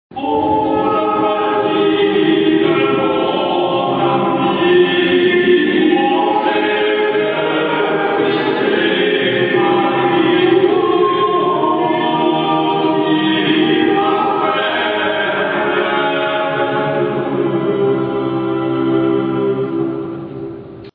建物の中の天井が高い, 広い場所で男声のコーラスの練習を聴くことが出来た. 音響(反響?)が良くて, ベースがよく響いていた.
コーラス(写真をクリックすると演奏開始)
chrus.mp3